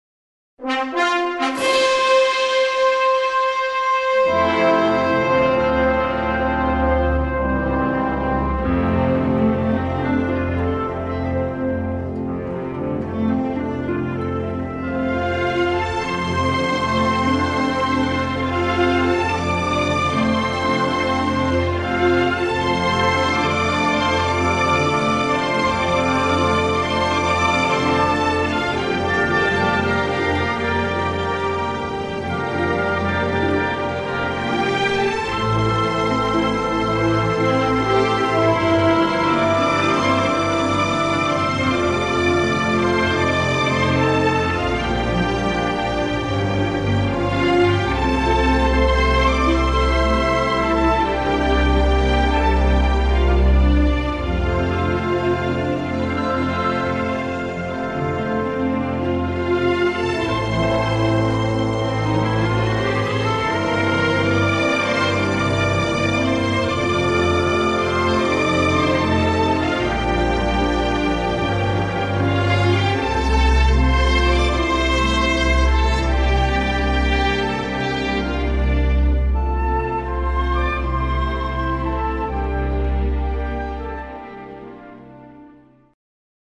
evocadora